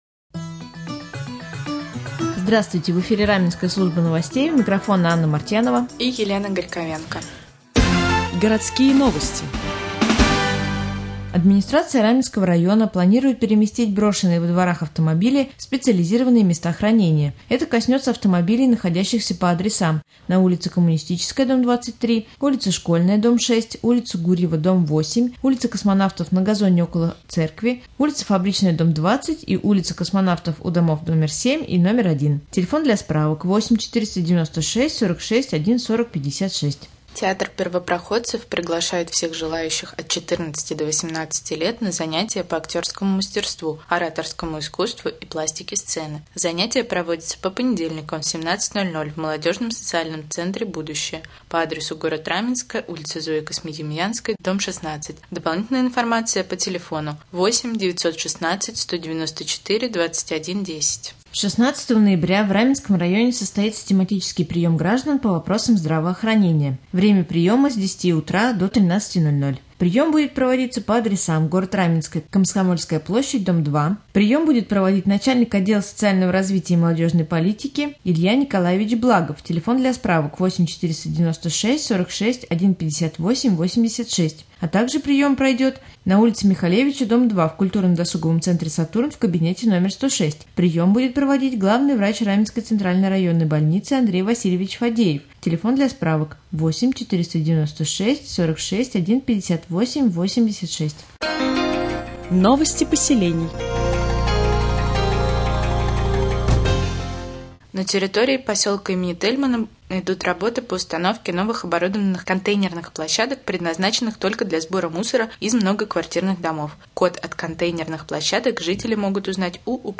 Сегодня в новостном выпуске на Раменском радио Вы узнаете, что рассказывают школьникам на парламентских уроках участники молодежного парламента Раменского района, как прошло Открытое Первенство Раменского района по самбо, а также последние областные новости и новости соседних районов.